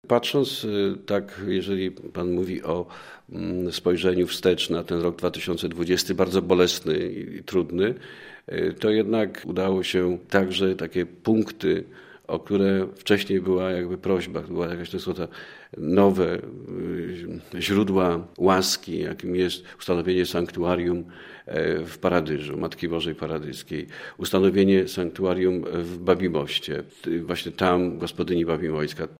’- To był ważny rok dla diecezji zielonogórsko-gorzowskiej – mówi biskup Tadeusz Lityński.